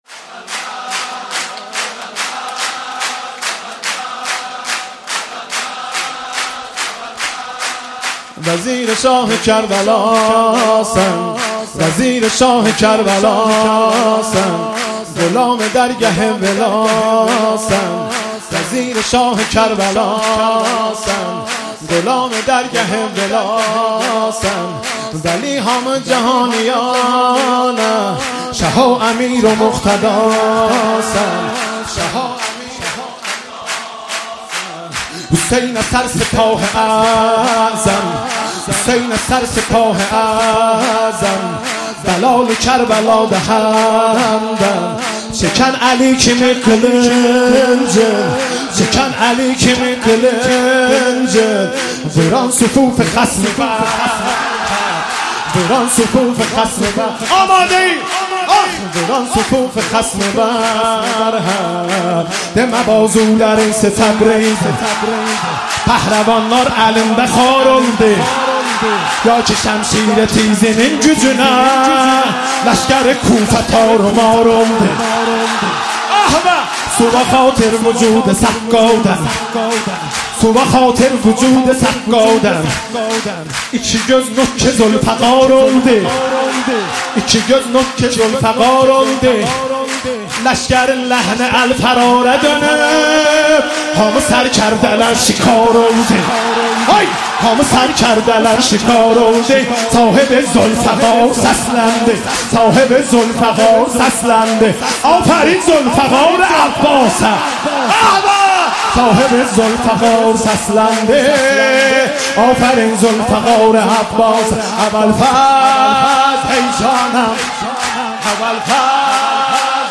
مولودی آذری